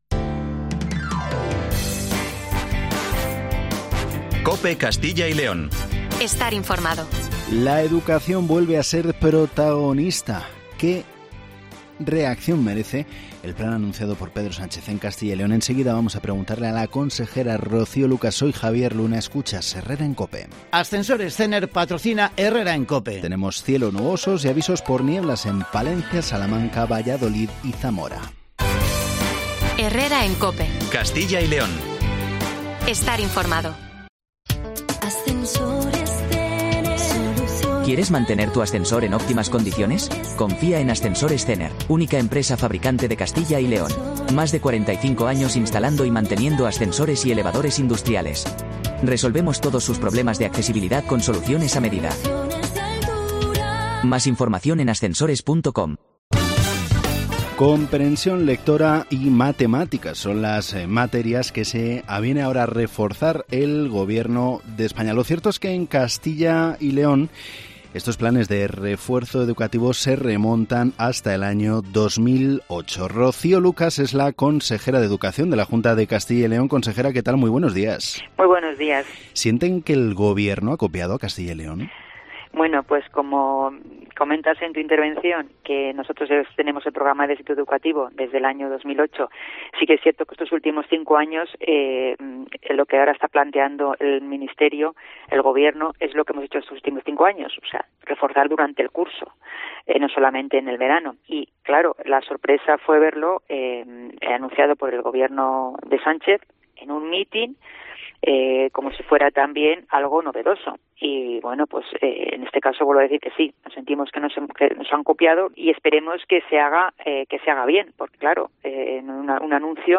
Hablamos con la Consejera de Educación, Rocío Lucas, tras el último anuncio de Pedro Sánchez: un plan de refuerzo de la comprensión lectora y las matematicas en Primaria y ESO.